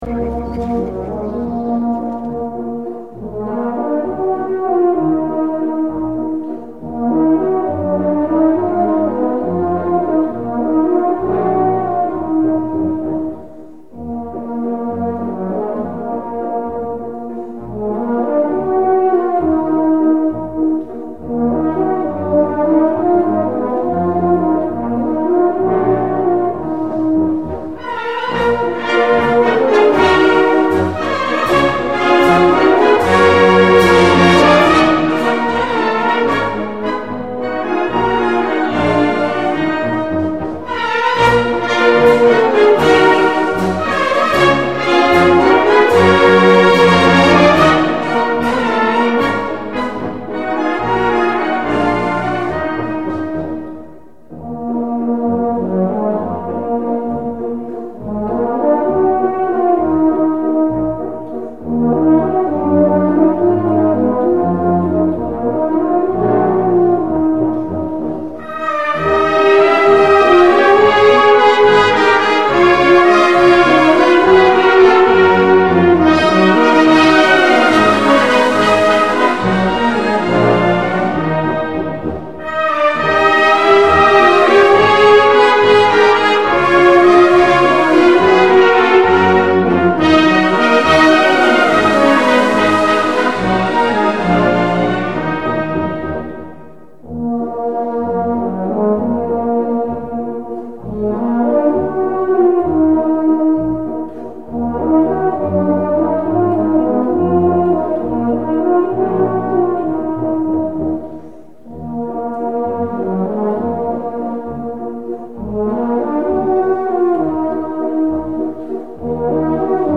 Помогите пожалуйста отыскать в хорошем качестве(не менее 128) вальс И.Шатрова-"На сопках Манжурии".
здесь (в исполнении Отдельного показательного оркестра Мин. обороны СССР).